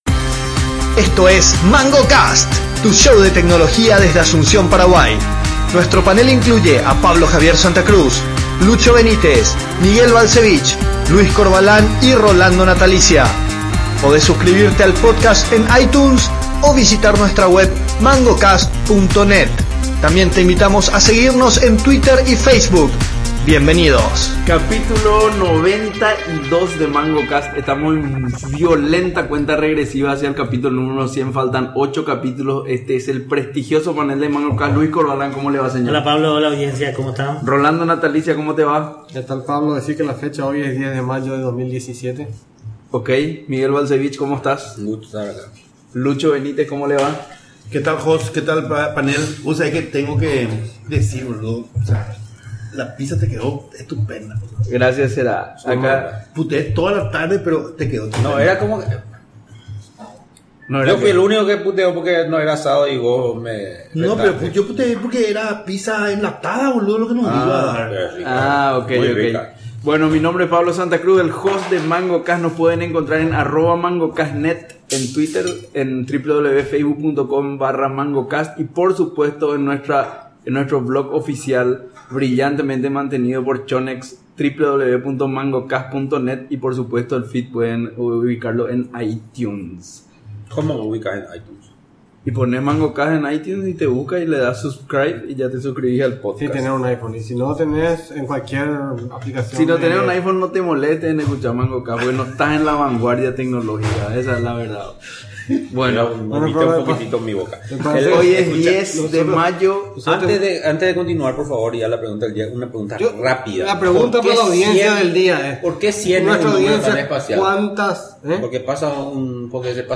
Varias tecnologías emergentes compiten por ser la siguiente gran cosa: realidad aumentada, realidad virtual, realidad mixta, inteligencia artificial, machine learning, robots, drones, vehículos autónomos, agentes, etc, y los panelistas procuraron pintar su visión del futuro.